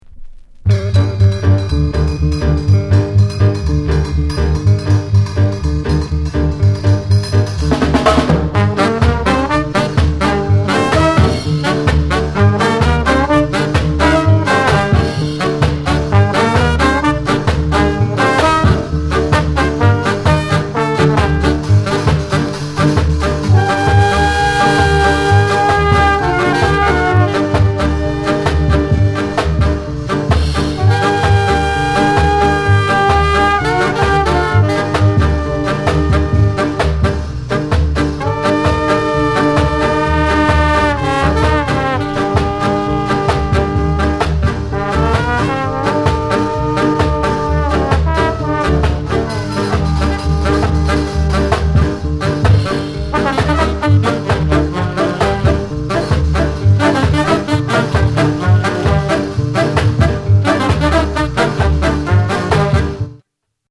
KILLER SKA INST